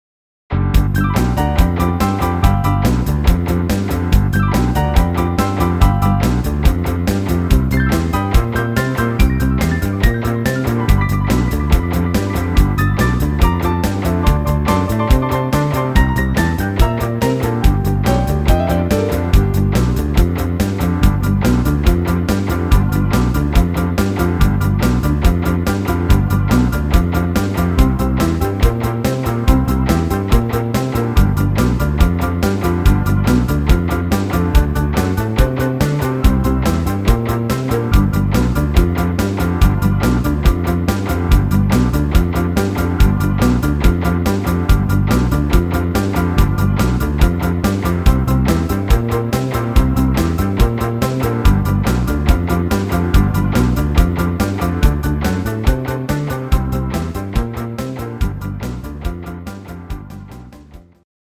Super rockige Version